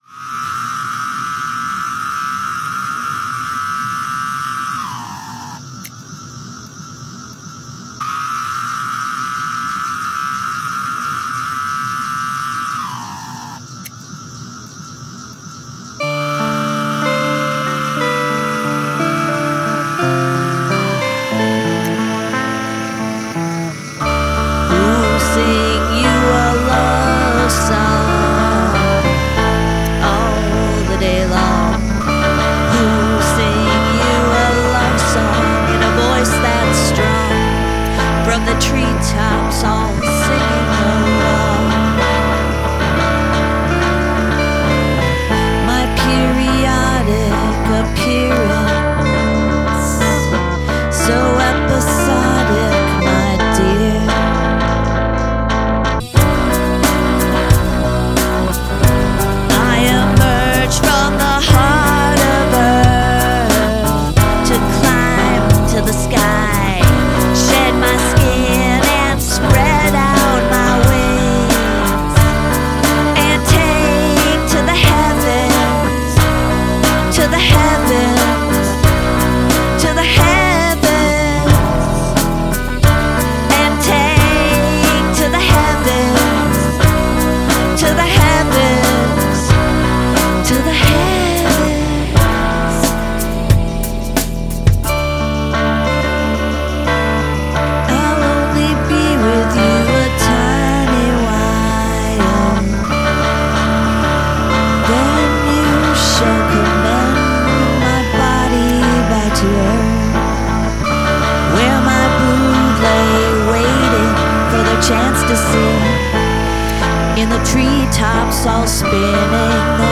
The heavier part at the end is a nice changeup.